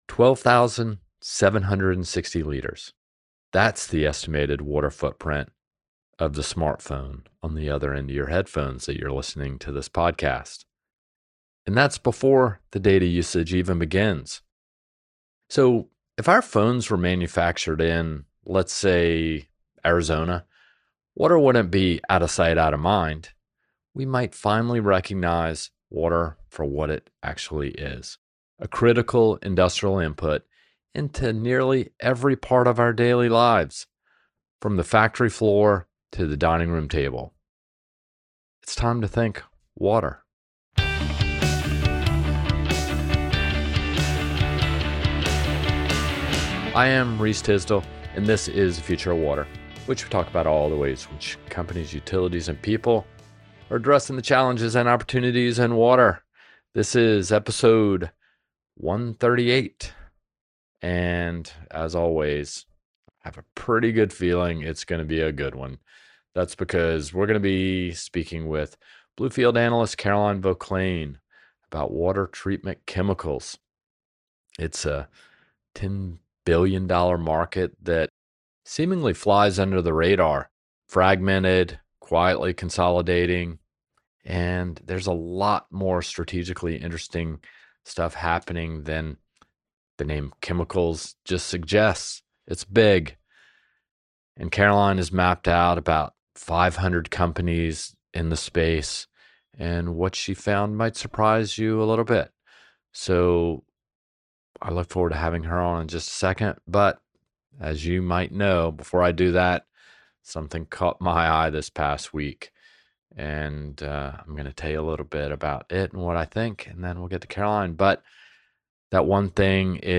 The conversation covers five key questions shaping this market: